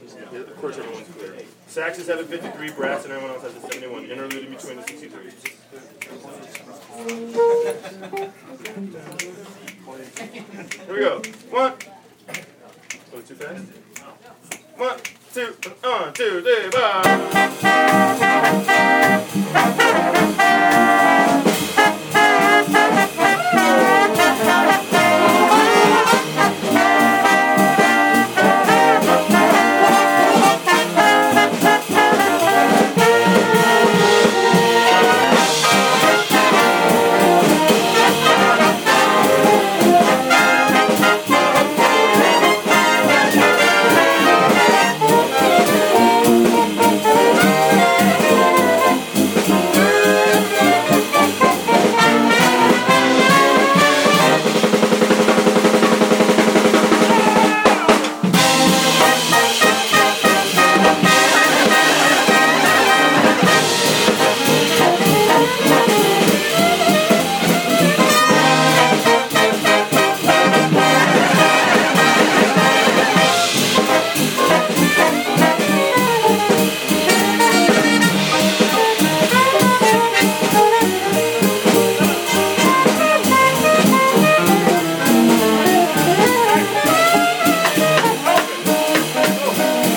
Bohemian Caverns band